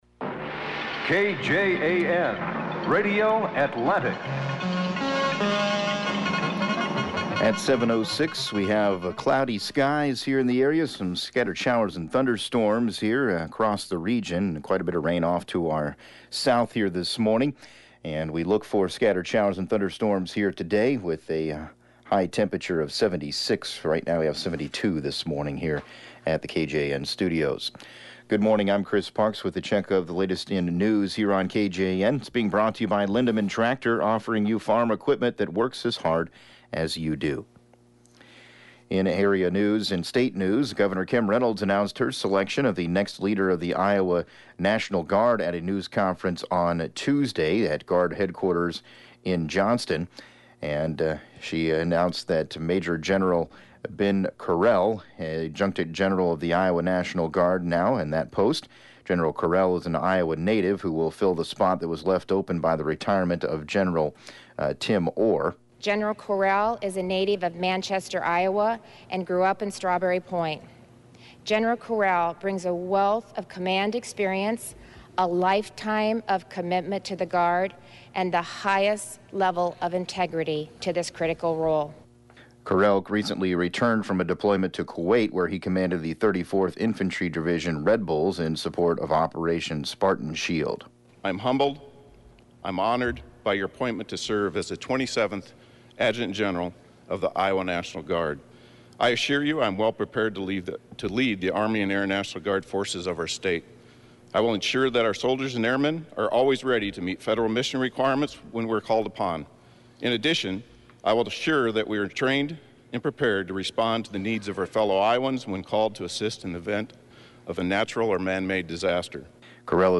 7AM Newscast 08/21/2019